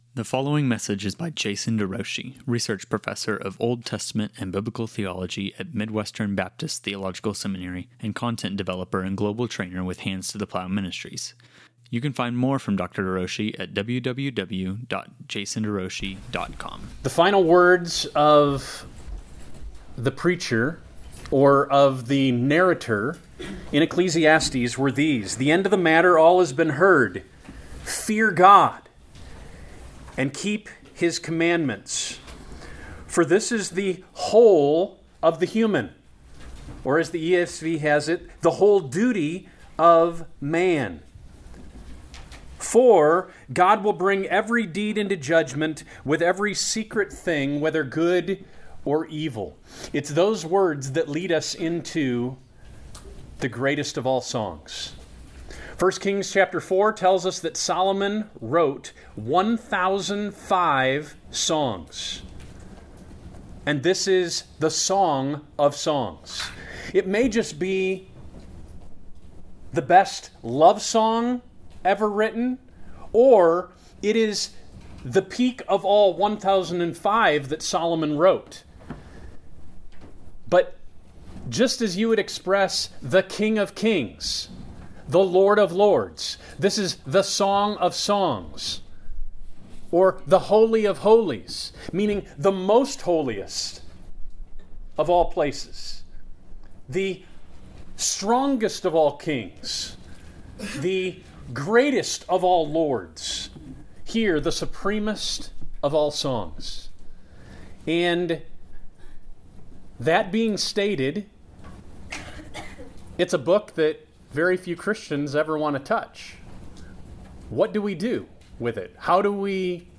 Lectures on Song of Songs
Song-of-Solomon-Lecture.mp3